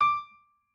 piano6_28.ogg